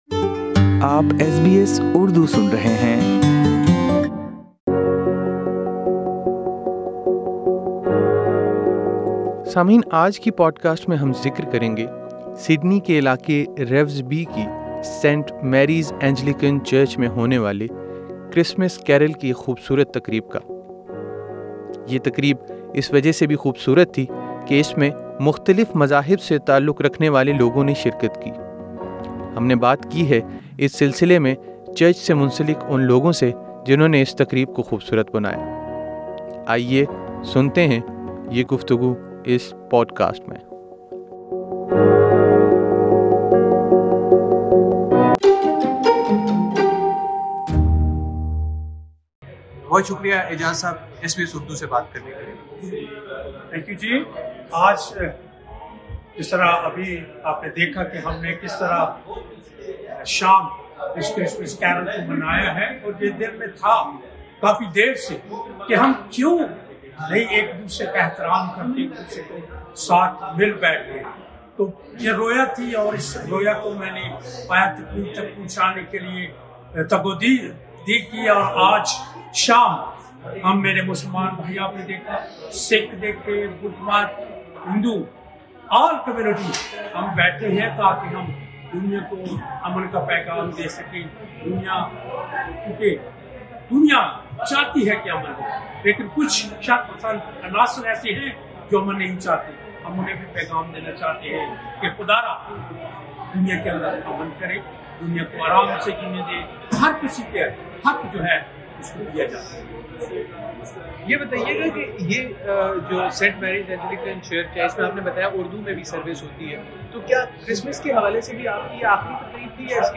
سینٹ مارک اردو اینجلیکن چرچ رِورزبی، سڈنی میں بین المذاہب ہم آہنگی کے لیے ایک کرسمس کیرل کی تقریب کا انعقاد کیا گیا- اس تقریب میں مختلف مذاہب سے تعلّق رکھنے والوں نے شرکت کی اور آپس میں رواداری سے مل کر رہنے پر زور دیا-